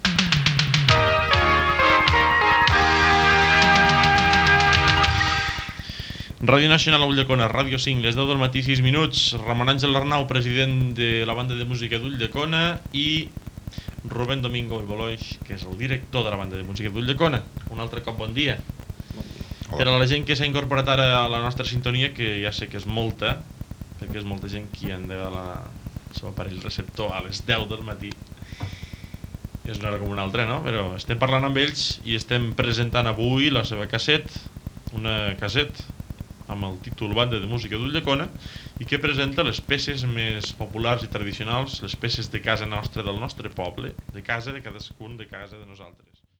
Identificació i presentació dels responsables de la banda de música d'Ulldecona.